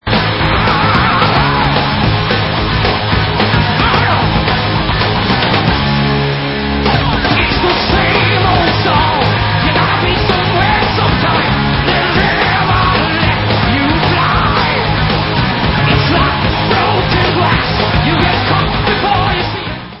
[Live]